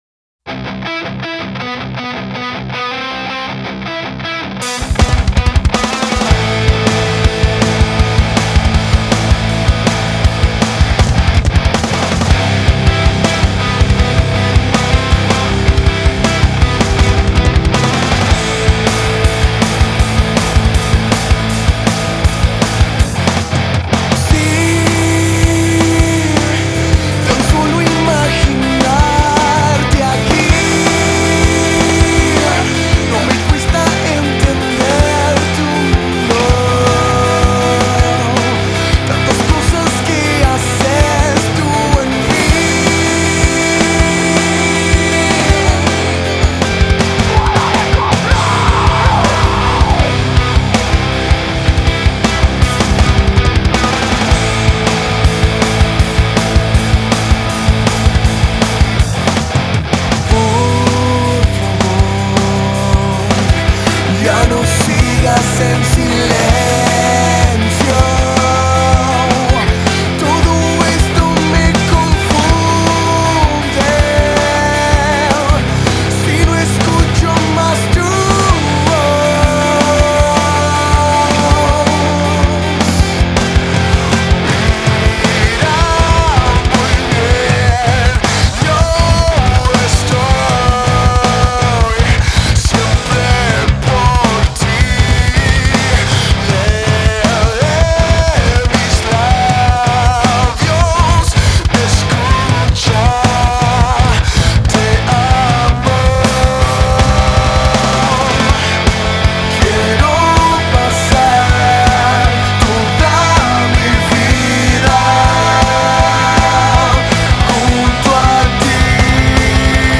General, Música Cristiana
Rock Alternativo.